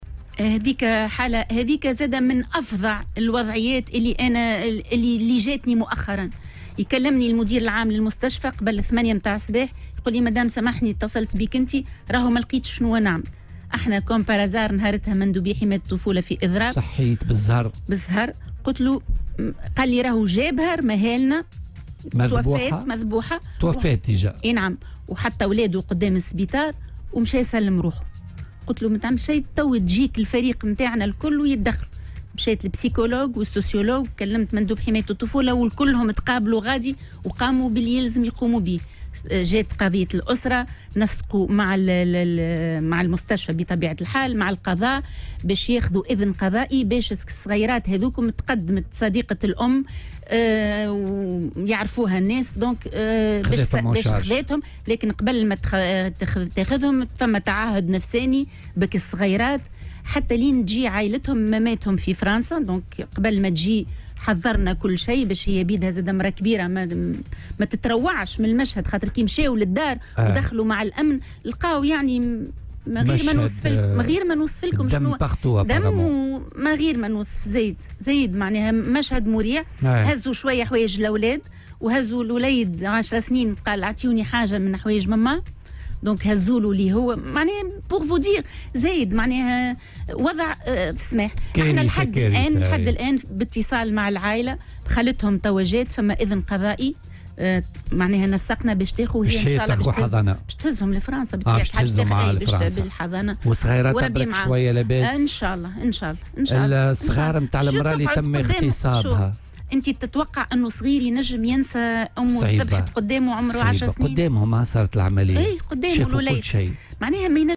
وأضافت في مداخلة لها اليوم في برنامج "بوليتيكا" أنها تلقت اتصالا هاتفيا من مدير مستشفى في اليوم الذي يُنفذ فيه مندوبو حماية الطفولة إضرابا عن العمل، ليخبرها بالواقعة وبأن الجاني قد ترك طفليه هناك (10 سنوات و7 سنوات) وسلّم نفسه للأمن.